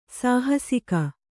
♪ sāhasika